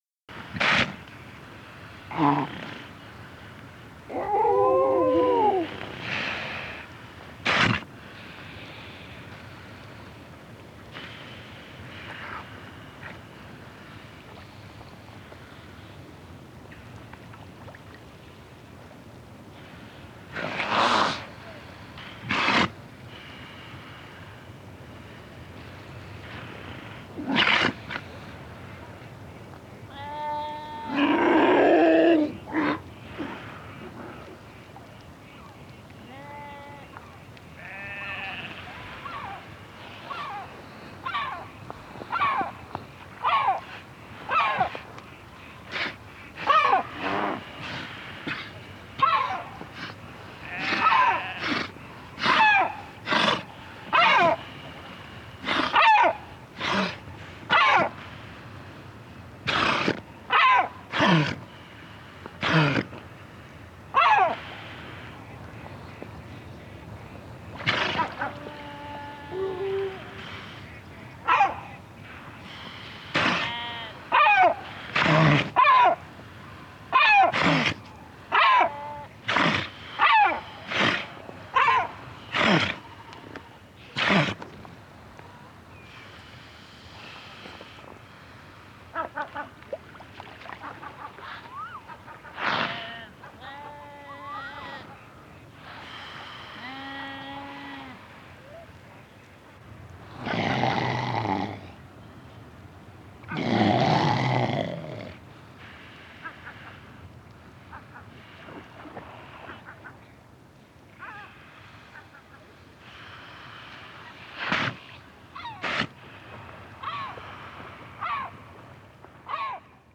Includes specially-compiled 60 minute CD of field recordings from the Gruenrekorder label.
05 seals